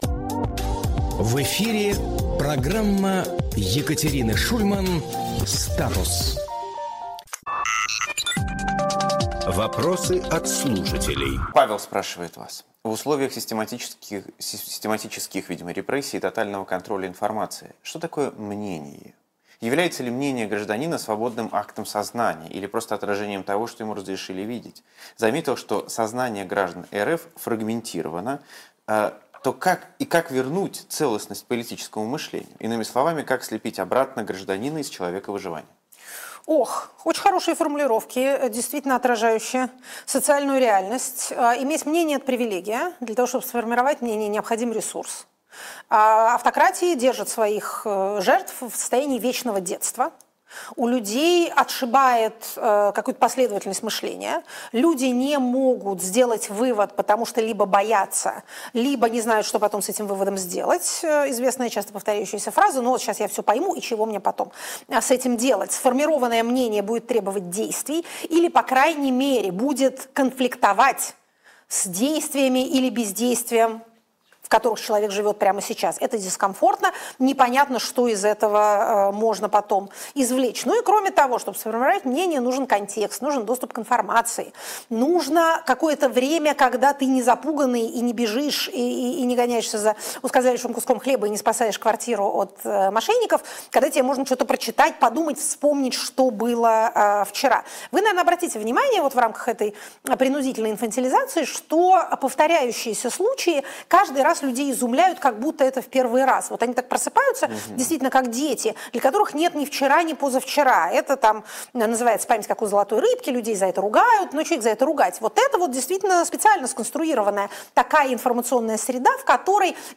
Фрагмент эфира от 02.12.25